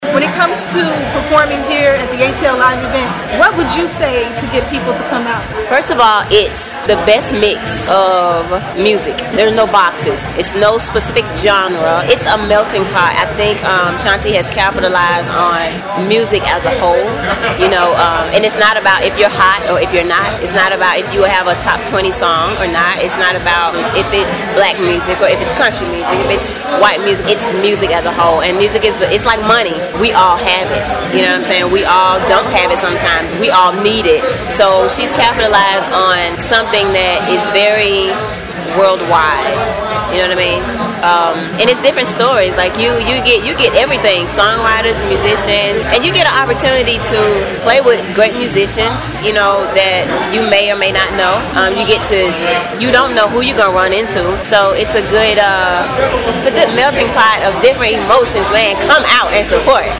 I interviewed each artist and asked what would you say to get people to come out to this event?